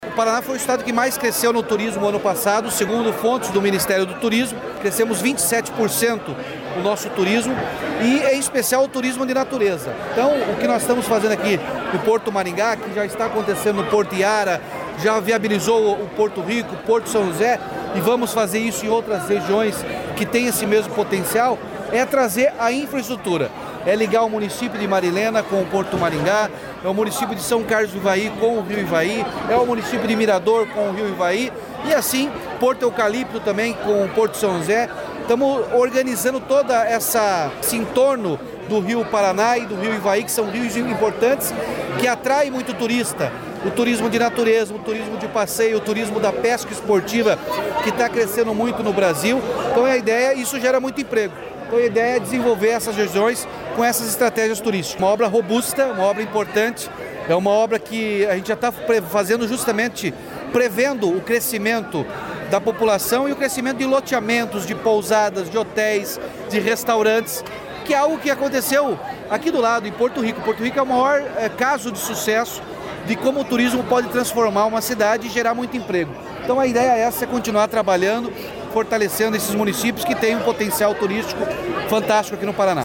Sonora do governador Ratinho Junior sobre o anúncio da pavimentação da área urbana de Marilena até o Porto Maringá